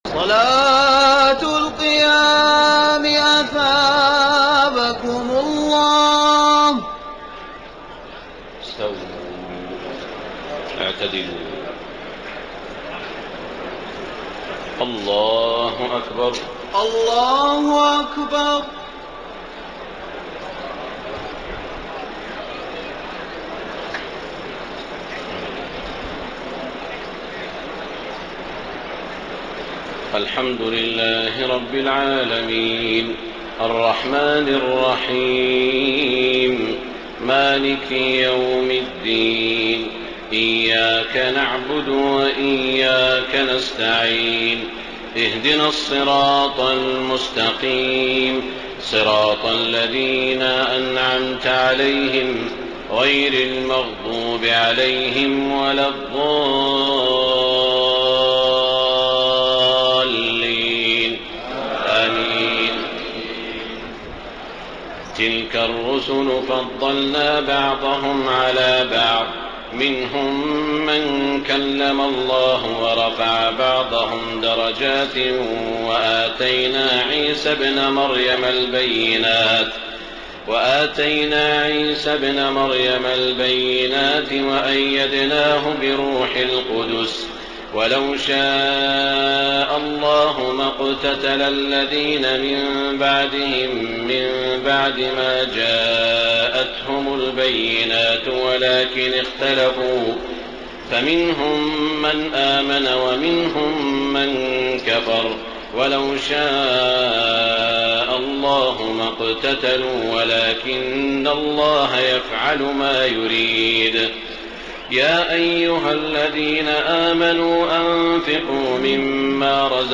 تهجد ليلة 23 رمضان 1433هـ من سورتي البقرة (253-286) و آل عمران (1-32) Tahajjud 23 st night Ramadan 1433H from Surah Al-Baqara and Aal-i-Imraan > تراويح الحرم المكي عام 1433 🕋 > التراويح - تلاوات الحرمين